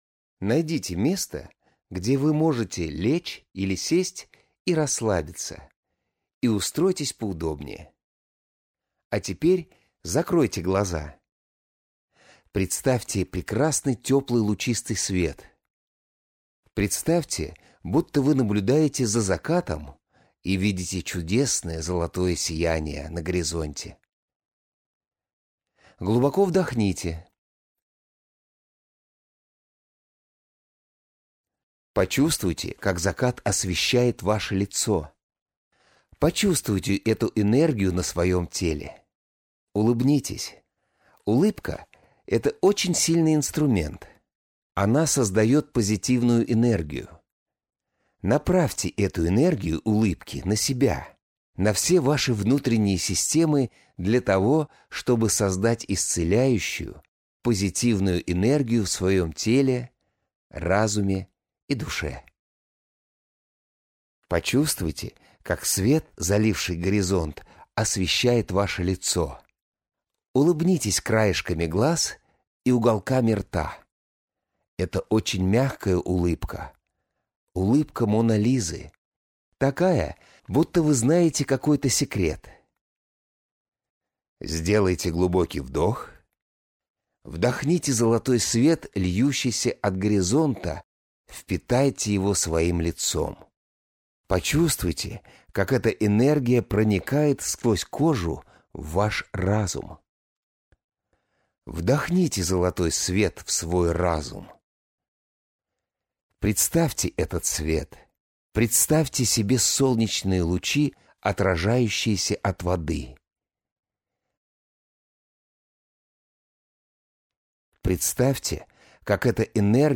МЕДИТАЦИЯ | Гармония Любви